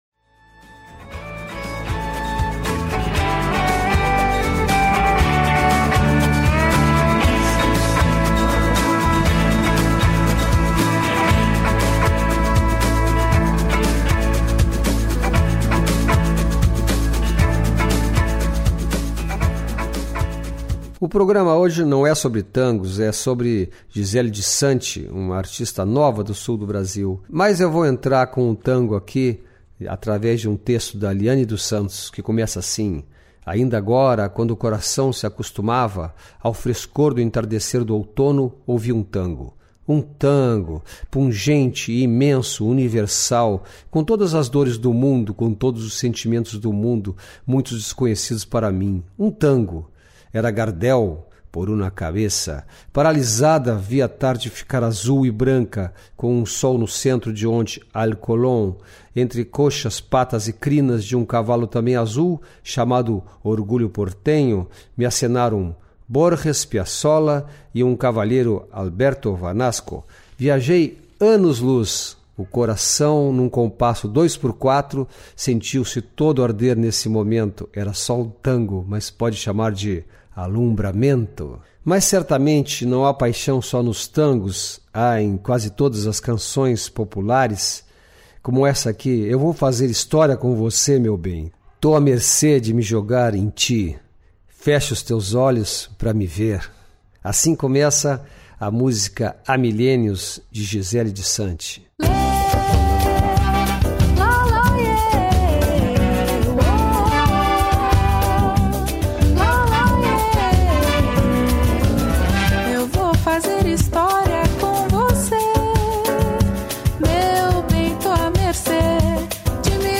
Voz de cristal, música que agrada a qualquer um. Melodia para sentar e escutar, serve de trilha sonora da vida, dançar sozinho ou junto a alguém, decorar a letra e cantar junto.